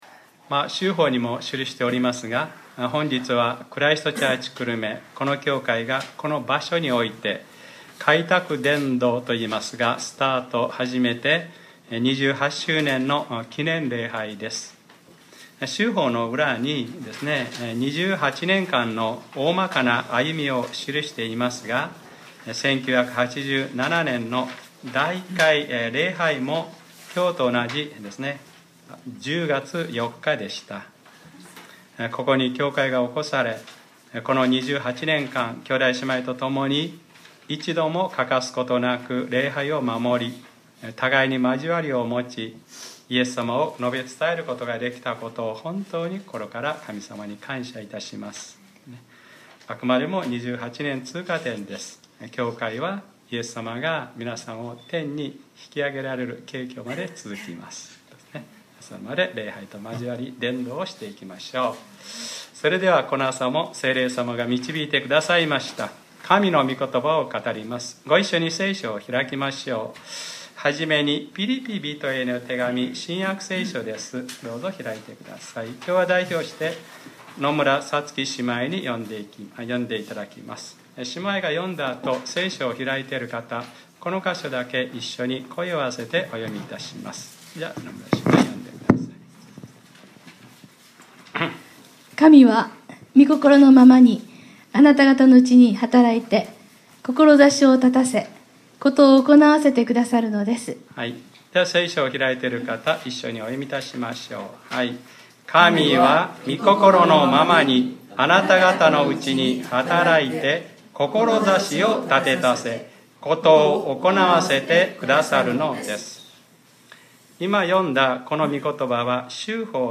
2015年10月04日（日）礼拝説教 『あなたがたのうちに働きかけてその願いを起こさせ』